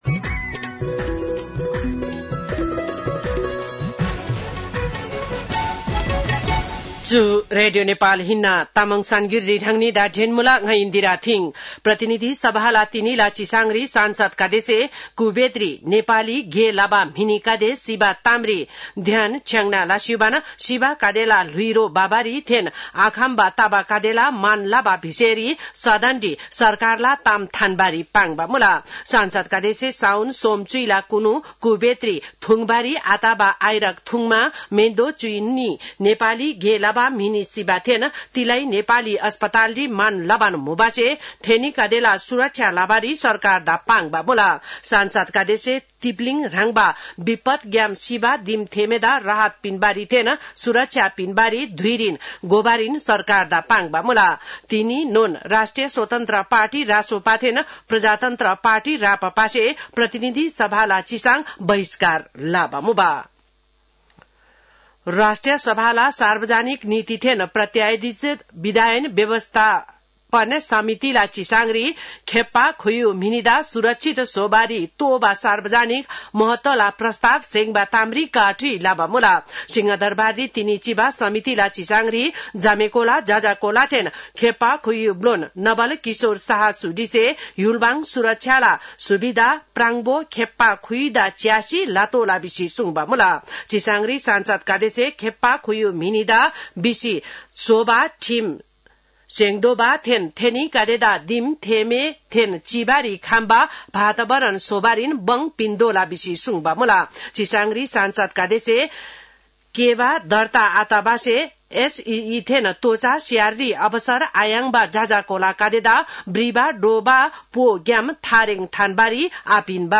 तामाङ भाषाको समाचार : ४ भदौ , २०८२